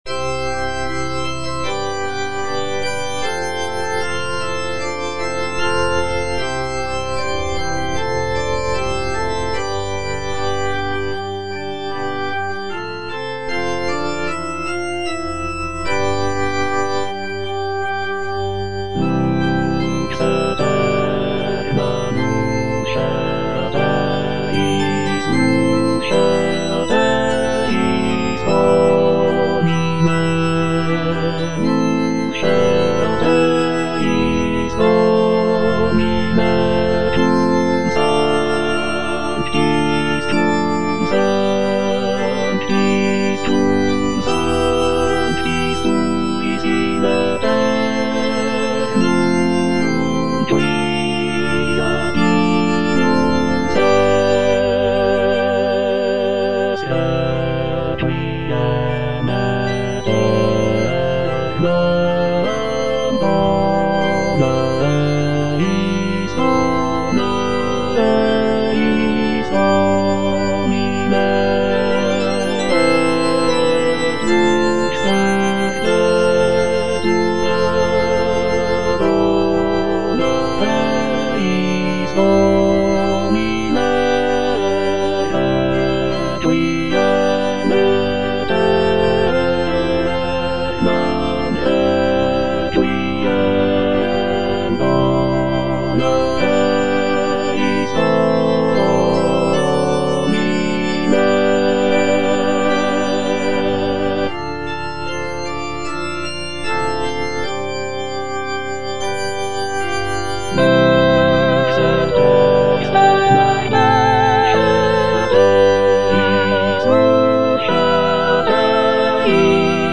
Alto (Emphasised voice and other voices) Ads stop